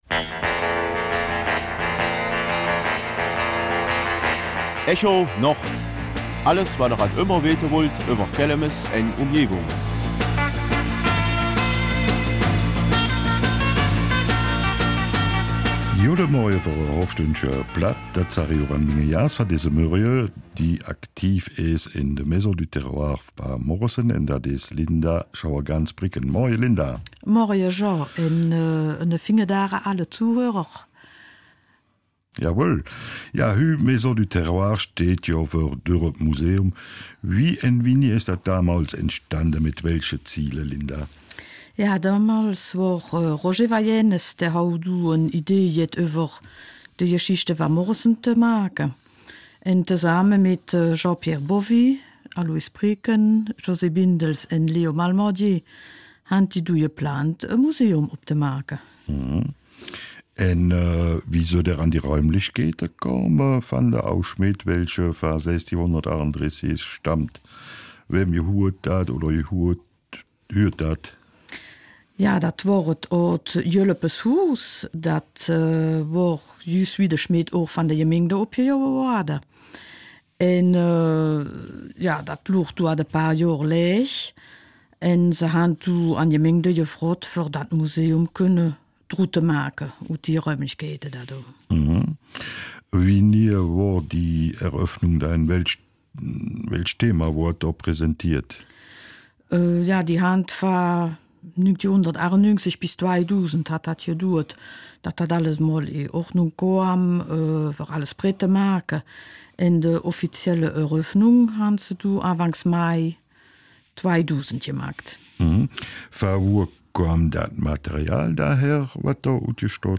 Kelmiser Mundart: Maison du Terroir Moresnet (Dorfmuseum)